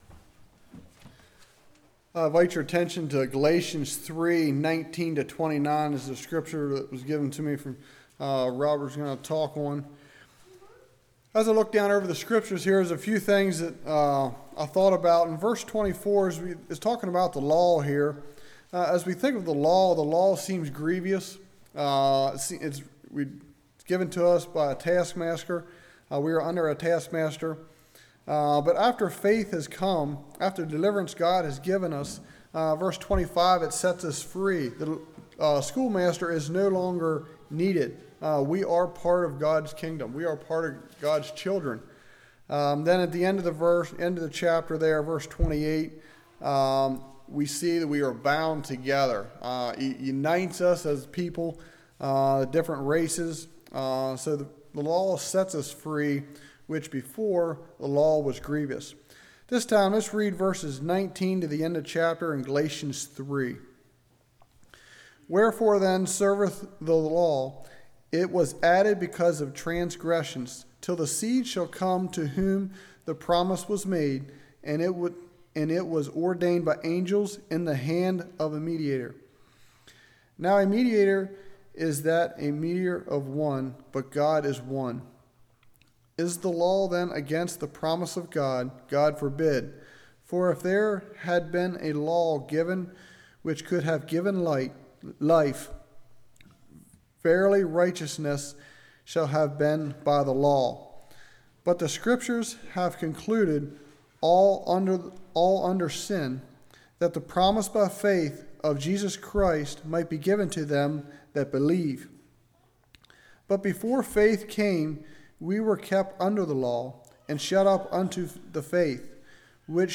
Passage: Romans 3:21-31 Service Type: Evening